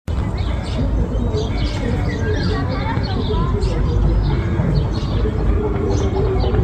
Golden Oriole, Oriolus oriolus
StatusVoice, calls heard